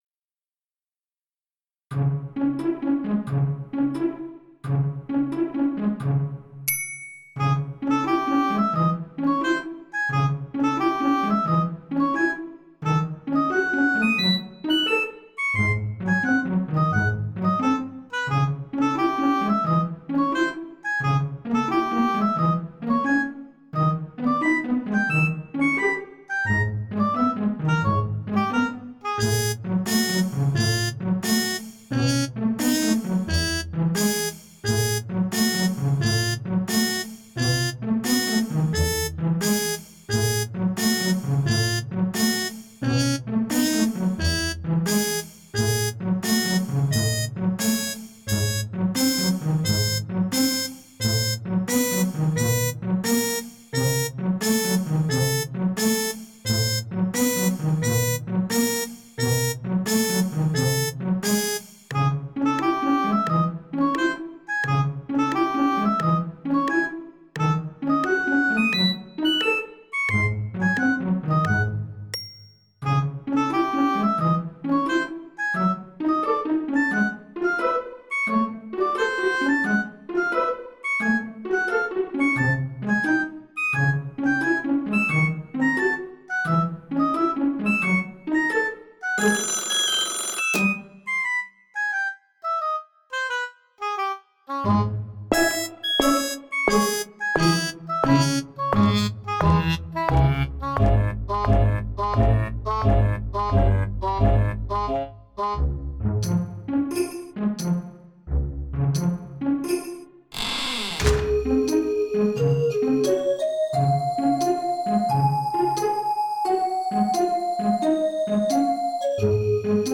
game BGM-like classical/folk and original music in mp3